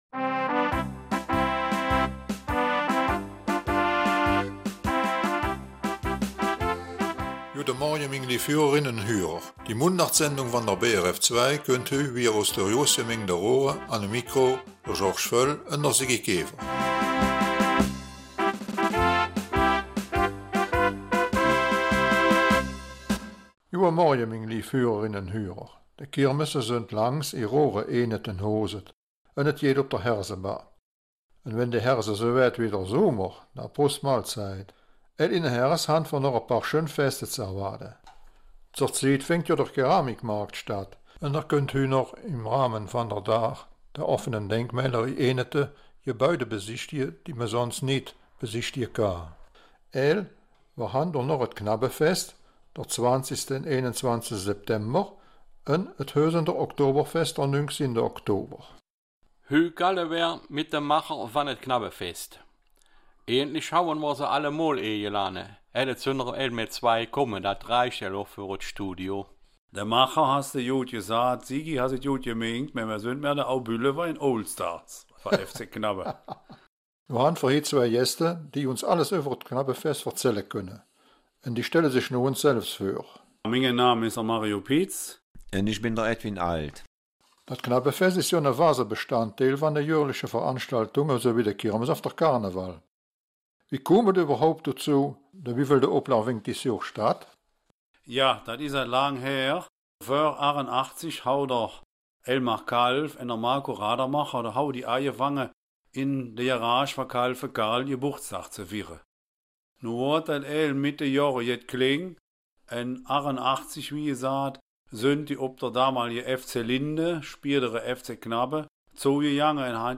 Raerener Mundart - 8. September